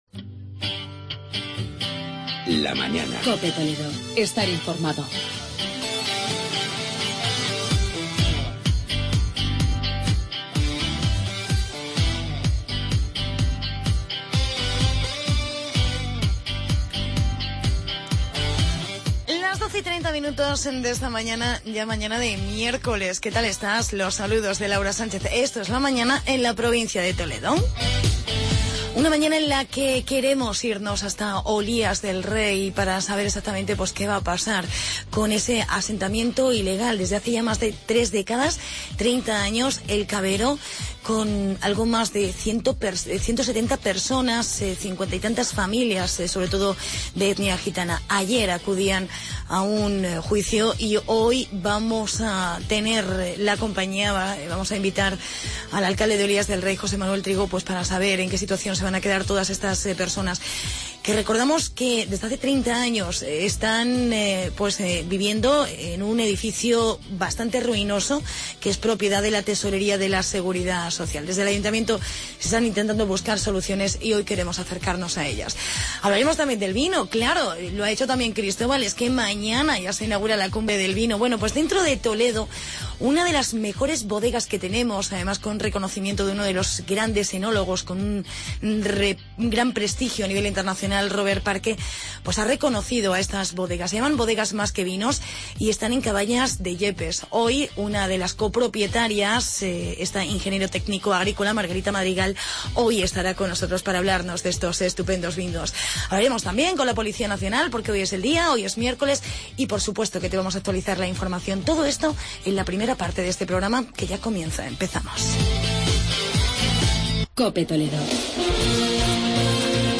Entrevistamos al alcalde de Olías, José Manuel Trigo